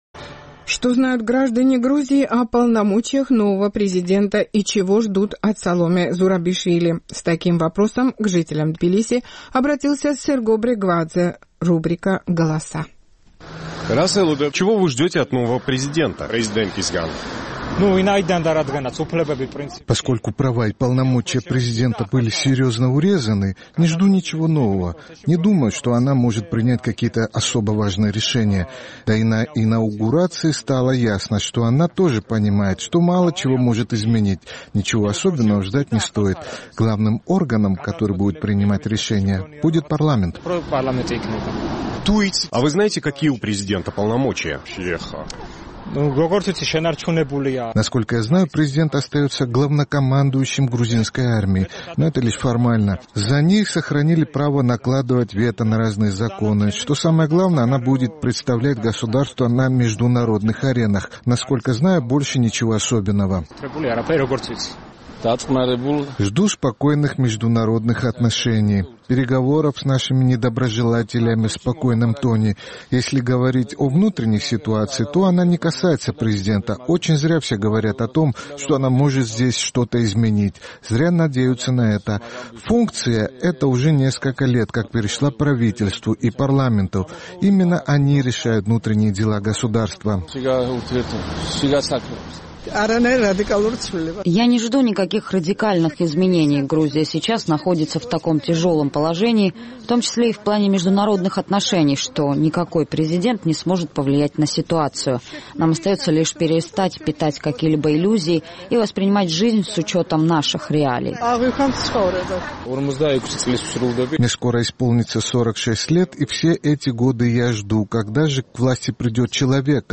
Что знают граждане Грузии о полномочиях нового президента и чего ждут от Саломе Зурабишвили? С таким вопросом к столичным жителям обратился наш тбилисский корреспондент.